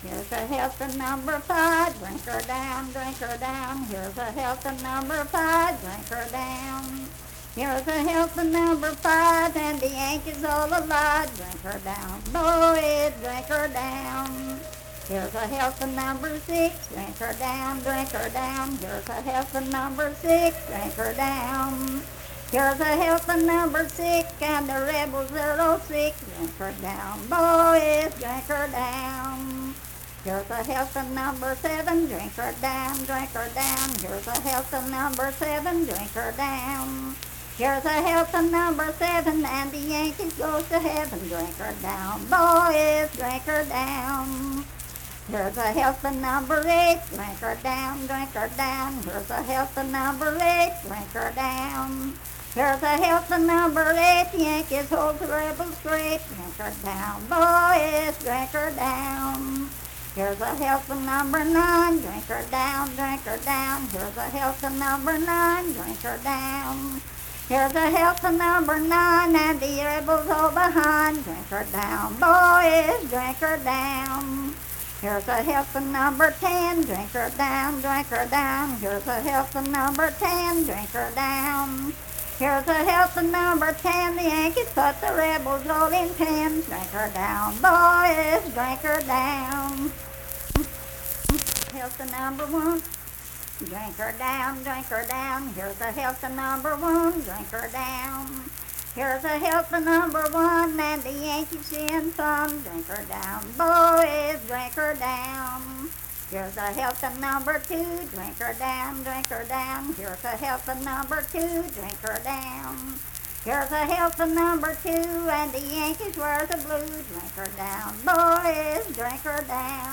Unaccompanied vocal music performance
Verse-refrain 10(4w/R).
Voice (sung)